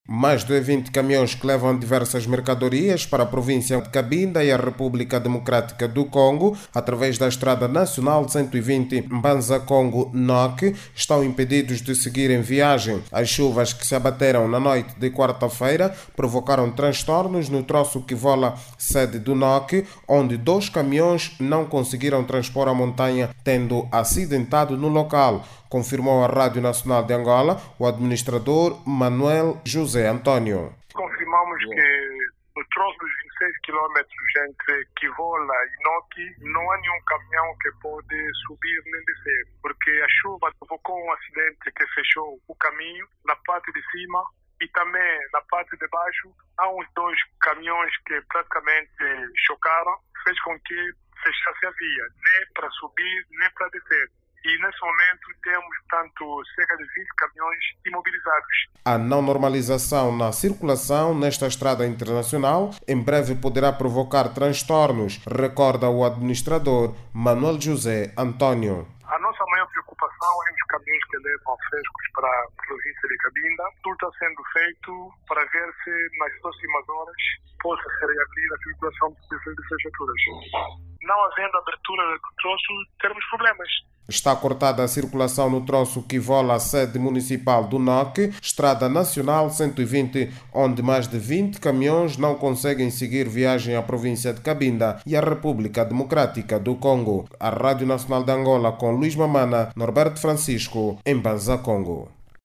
A chuva intensa que se abate sobre a região provocou danos na via, tornando-a intransitável. Como consequência, vários camiões carregados de mercadorias estão retidos, impossibilitados de seguir viagem em direção à República Democrática do Congo. Saiba mais dados no áudio abaixo com o repórter